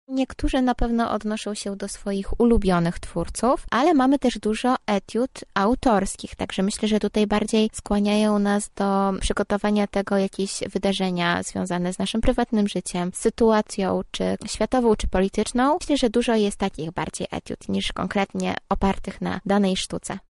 O inspiracjach młodych twórców mówi jedna ze studentek